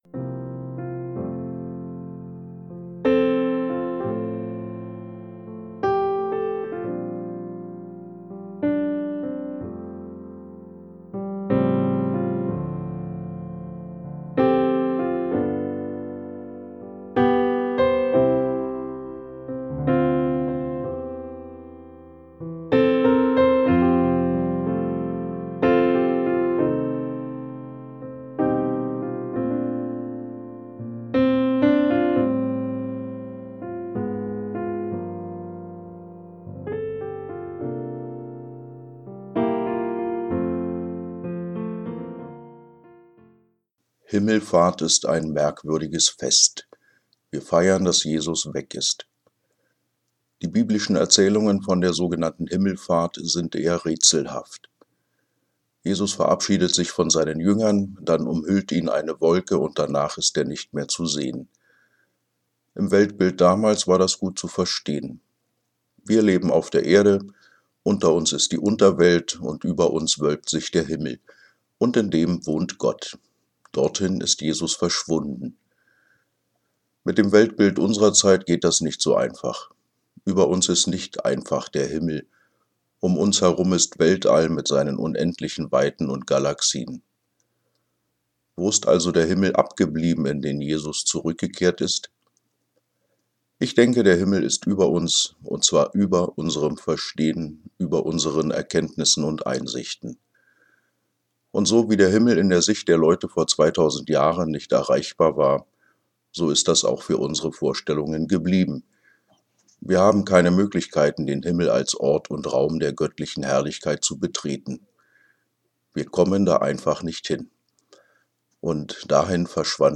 Musik: